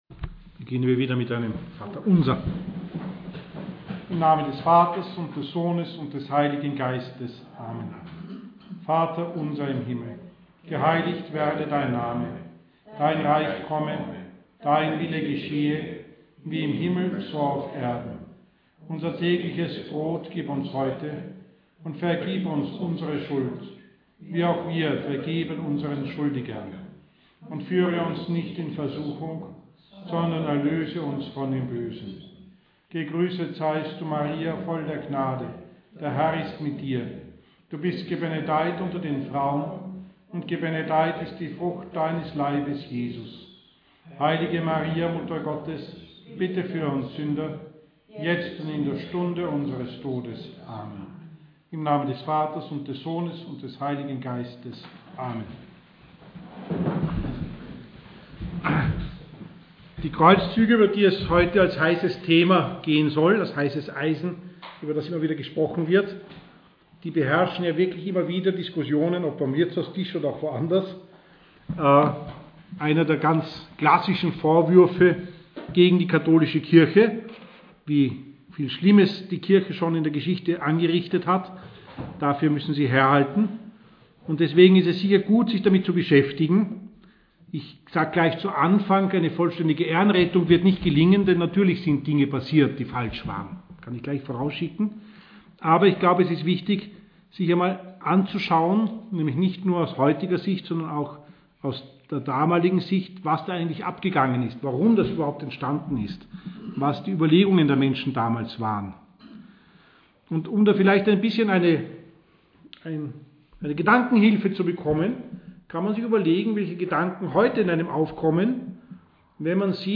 Diese Katechesereihe über die "heißen Eisen der Kirche" ist sowohl für Katholiken gedacht, die ihre Mutter Kirche verteidigen wollen, wo sie zu Unrecht angegriffen wird, wie auch für Kritiker, die sich redlich darüber informieren wollen, worüber sie sprechen.